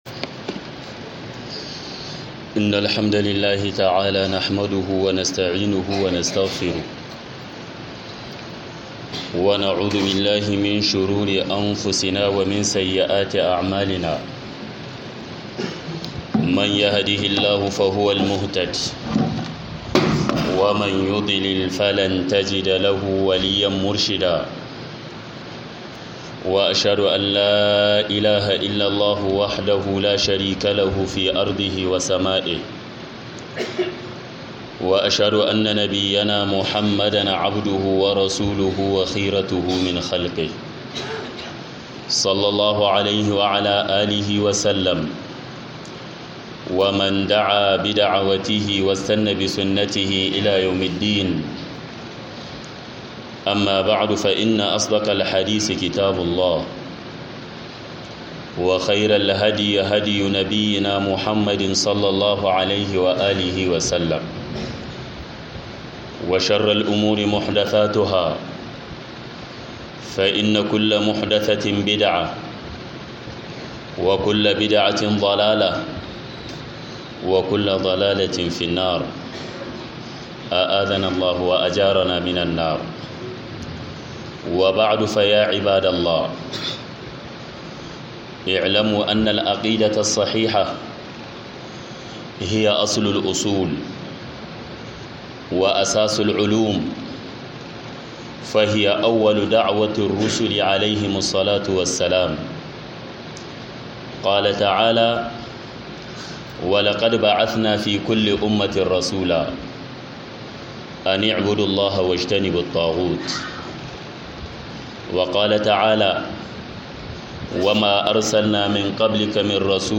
HUDUBA KIRA-ZUWA-GA-INGANTACIYAR-AKIDA - HUDUBA